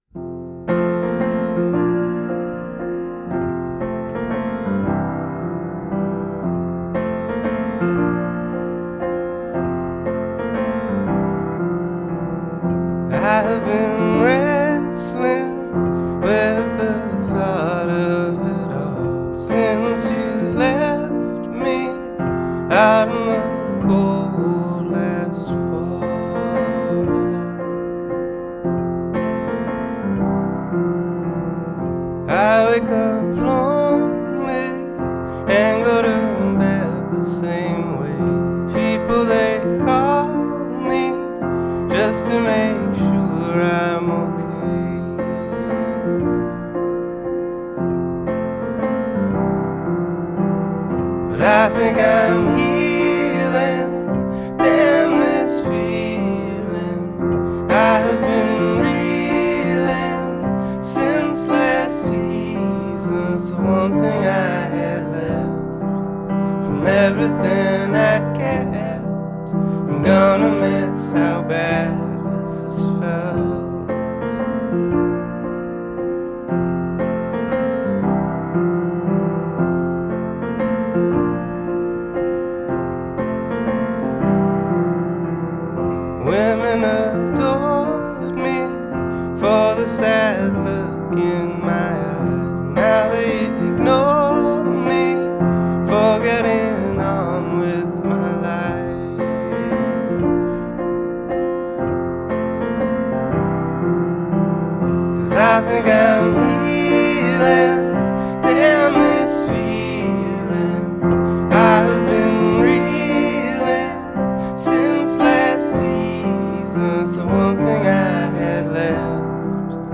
gravelly delivery
the simple piano riff that propels